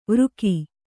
♪ vřki